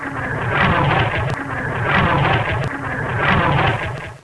FLYOVER.WAV